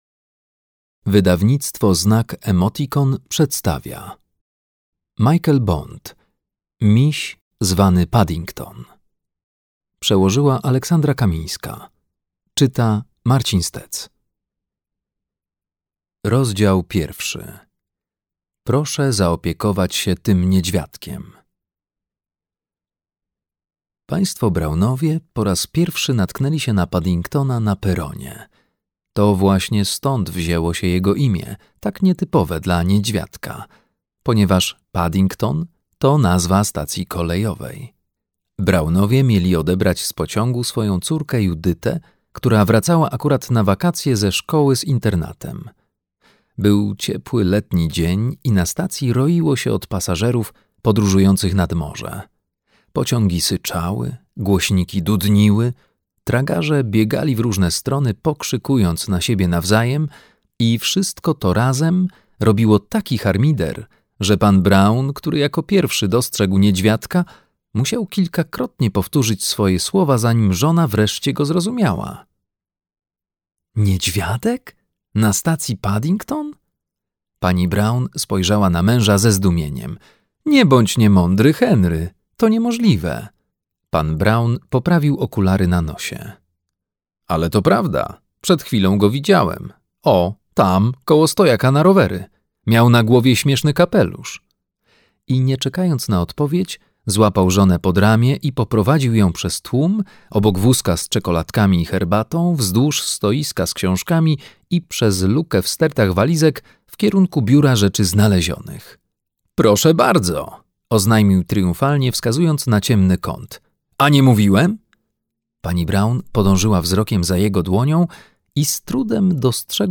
Miś zwany Paddington - Michael Bond - ebook + audiobook + książka